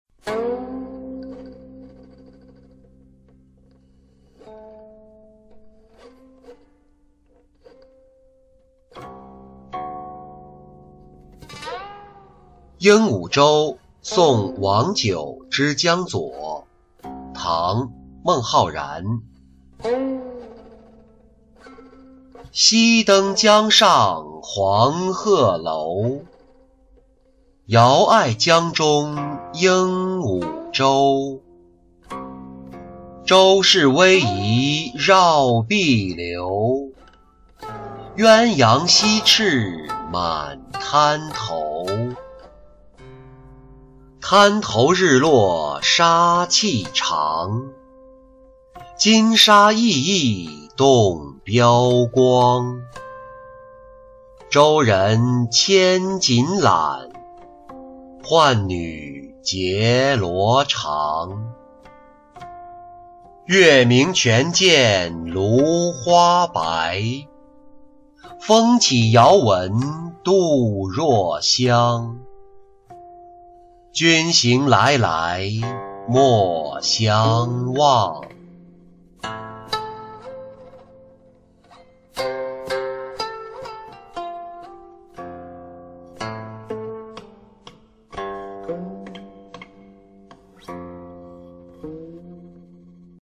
鹦鹉洲送王九之江左-音频朗读